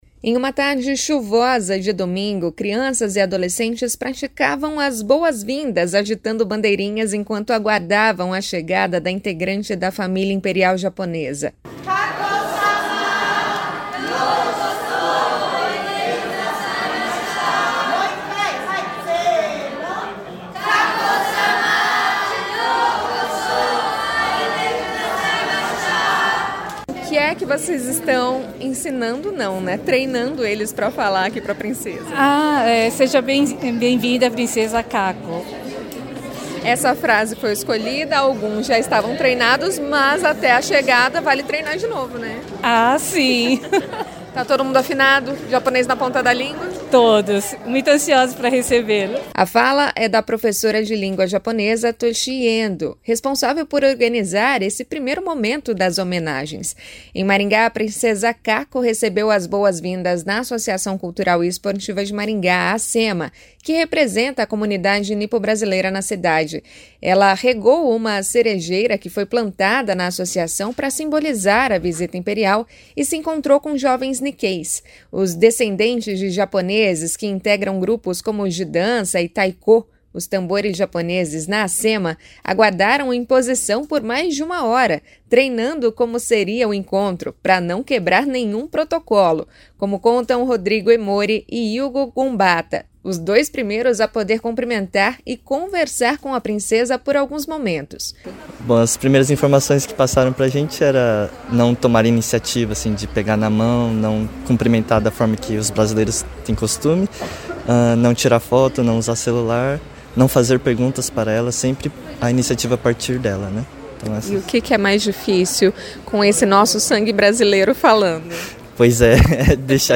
O deputado federal Luiz Nishimori falou sobre a tradição da família imperial em visitas à cidade, que já se repete há gerações.